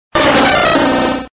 Cri de Roigada dans Pokémon Diamant et Perle.